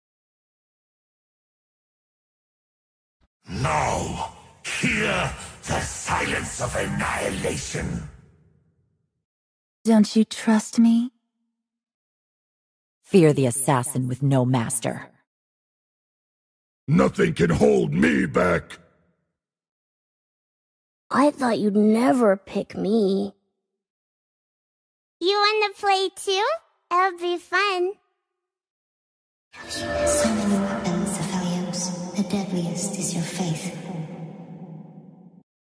Voice Effects League of sound effects free download
Voice Effects - League of Legends Champions Voices PART 1